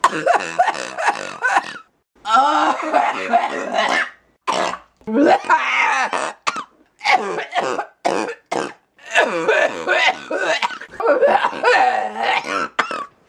Play, download and share Glozell coughing original sound button!!!!
glozell-coughing.mp3